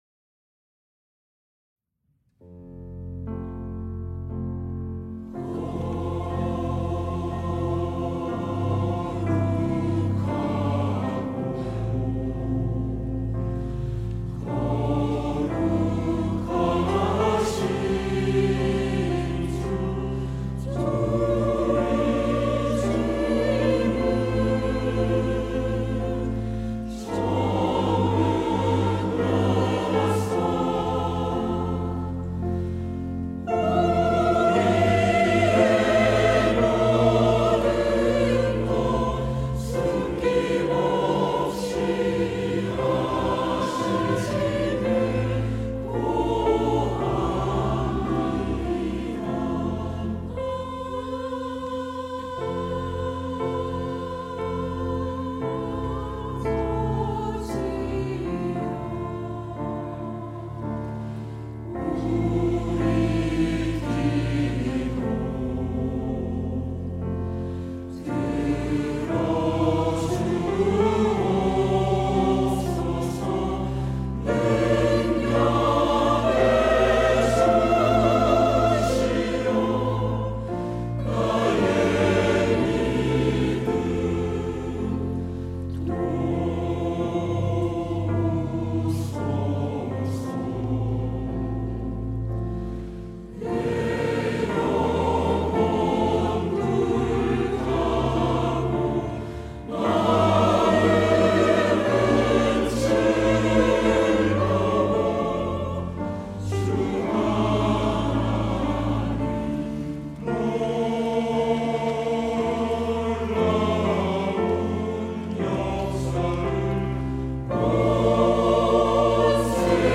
시온(주일1부) - 하늘의 아버지
찬양대